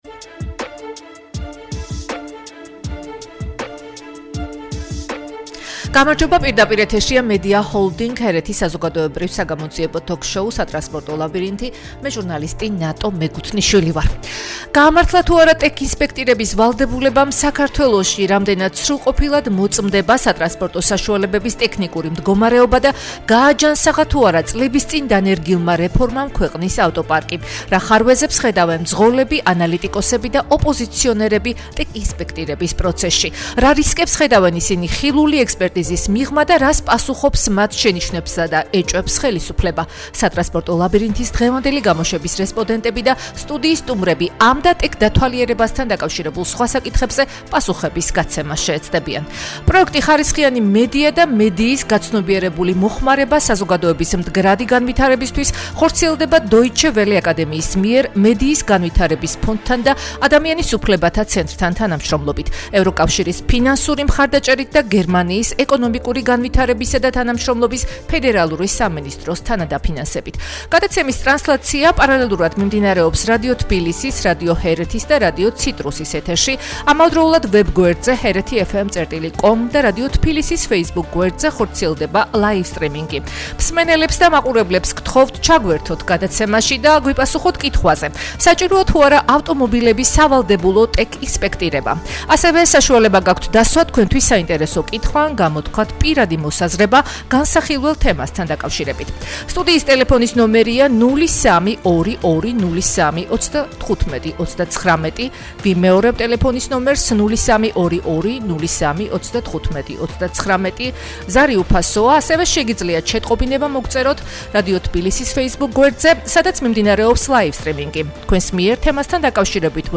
ტექინსპექტირება / ტოკ-შოუ 15.11.2024 – HeretiFM
-„სატრანსპორტო ლაბირინთის“ დღევანდელი გამოშვების რესპონდენტები და სტუდიის სტუმრები ამ და, ტექდათვალიერებასთან დაკავშირებულ სხვა კითხვებზე პასუხების გაცემას შეეცდებიან.